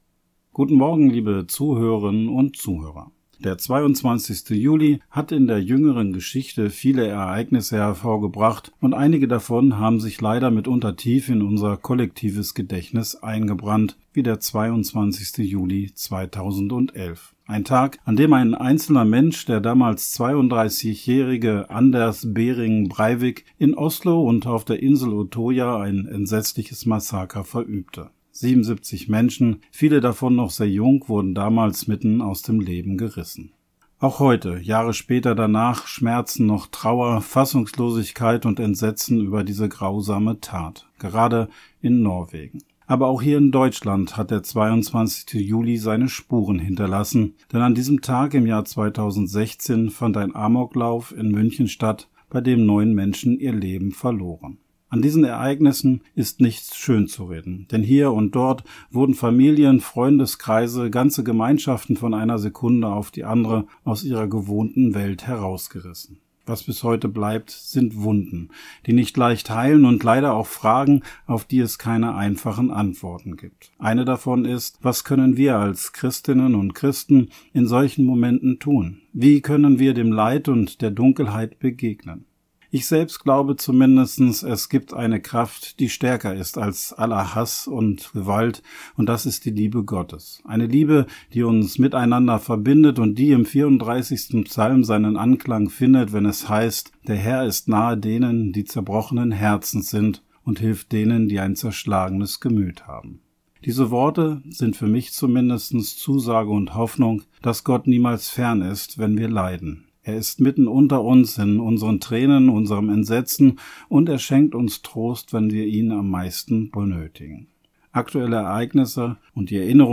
Radioandacht vom 22.07.